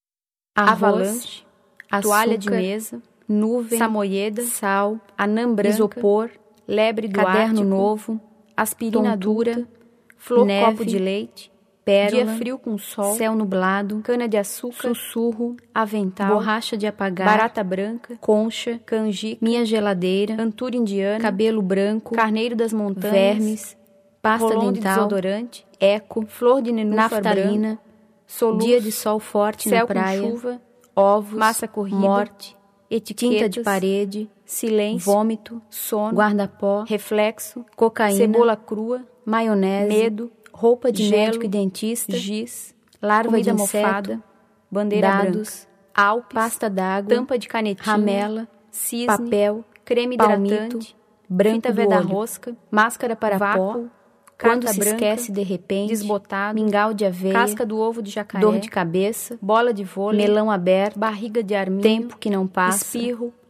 Publicação sonora (CD de áudio, papel arroz e embalagem, com impressão em jato de tinta)